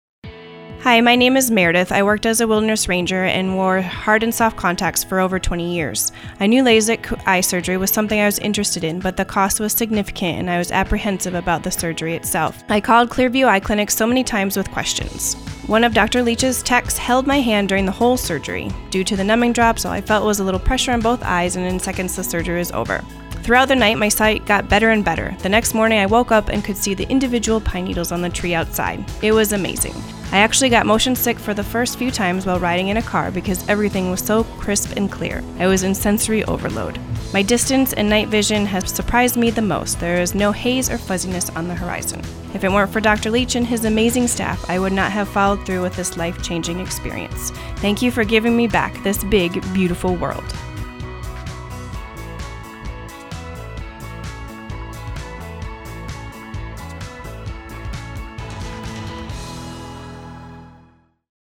Here’s that spot as recorded and mixed by a busy production person at a local radio station, prior to my involvement.